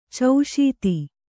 ♪ cauśiti